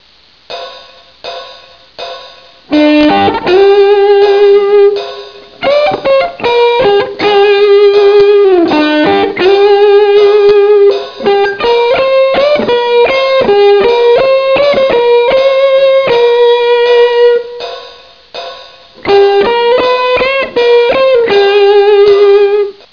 Посмотрите на Пример 3, являющийся фразой, которую я играл во вступлении к "The Thrill is Gone".